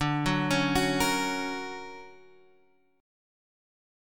D+M7 chord